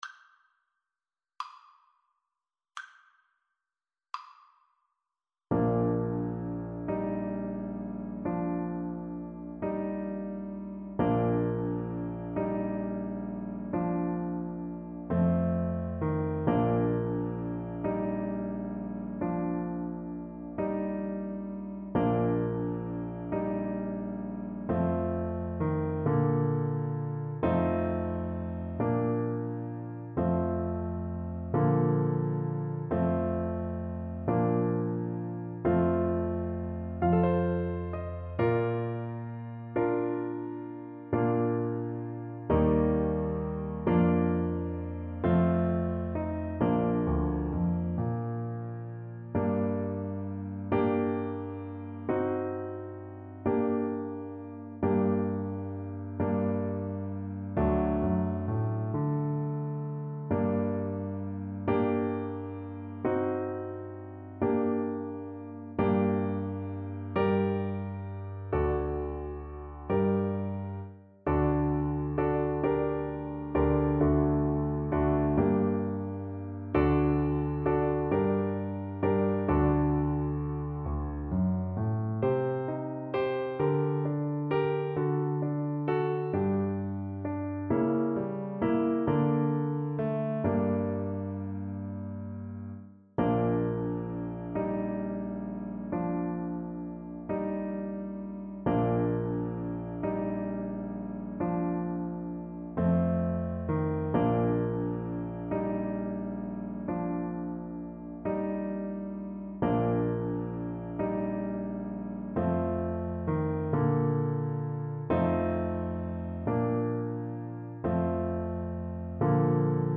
Clarinet version
. = 60 Andante
6/8 (View more 6/8 Music)
Clarinet  (View more Easy Clarinet Music)
Classical (View more Classical Clarinet Music)